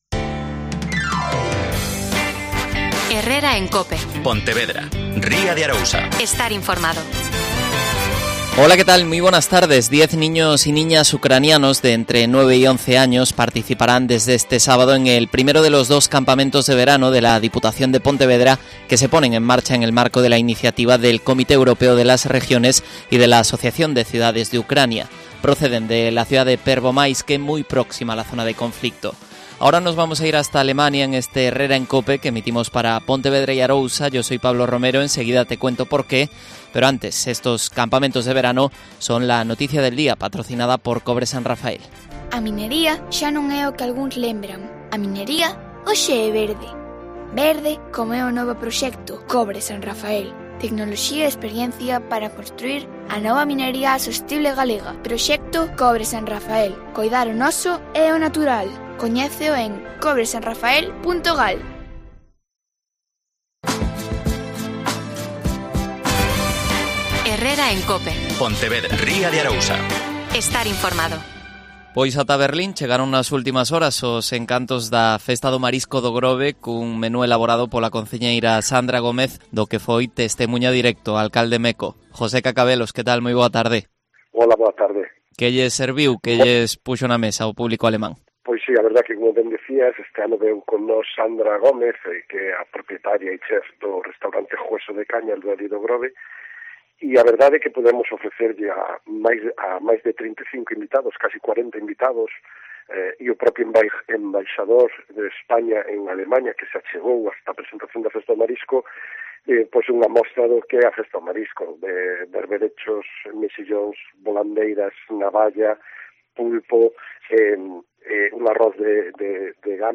José Cacabelos. Alcalde de O Grove.